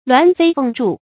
鸾飞凤翥 luán fēi fèng zhù
鸾飞凤翥发音
成语注音 ㄌㄨㄢˊ ㄈㄟ ㄈㄥˋ ㄓㄨˋ